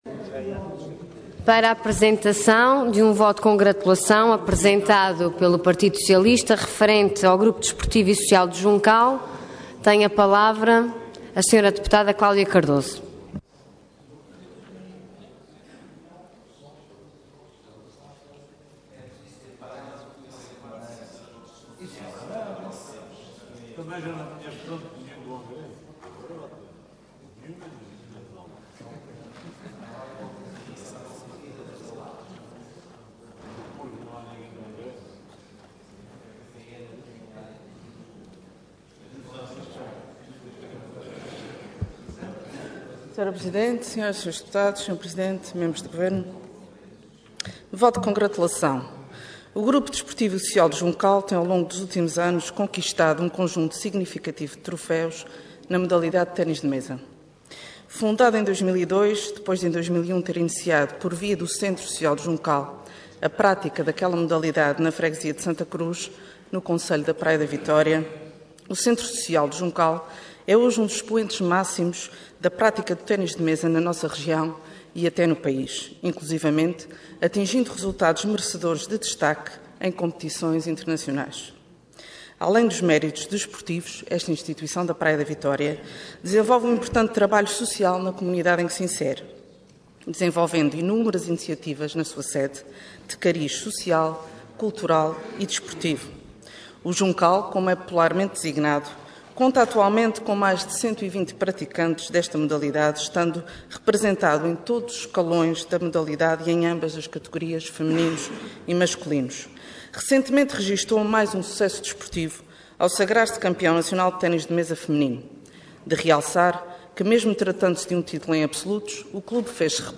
Website da Assembleia Legislativa da Região Autónoma dos Açores
Detalhe de vídeo 8 de maio de 2014 Download áudio Download vídeo Processo X Legislatura Título alcançado pelo Clube Desportivo do Juncal Intervenção Voto de Congratulação Orador Cláudia Cardoso Cargo Deputada Entidade PS